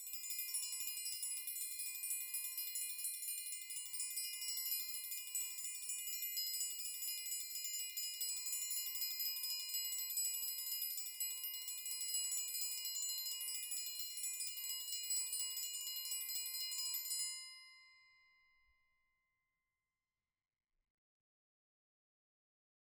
Triangle6-Roll_v2_rr1_Sum.wav